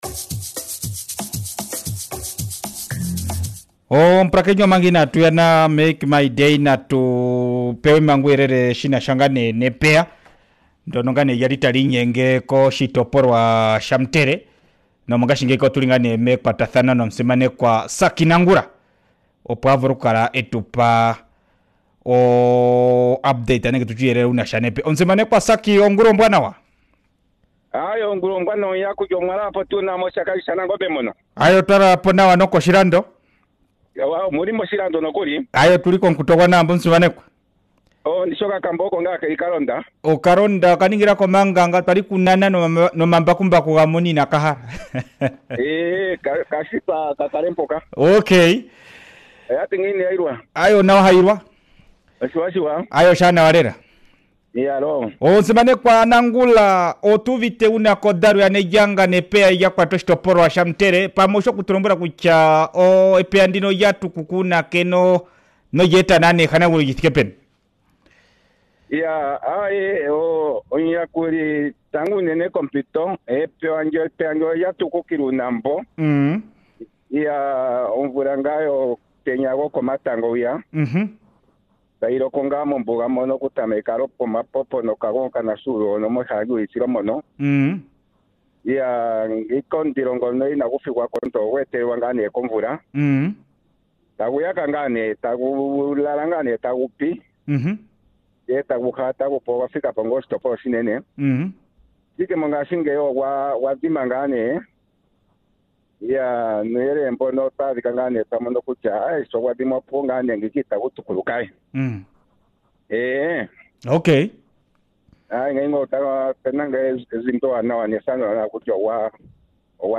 20 Mar Interview with Hon. Sacky Nangula .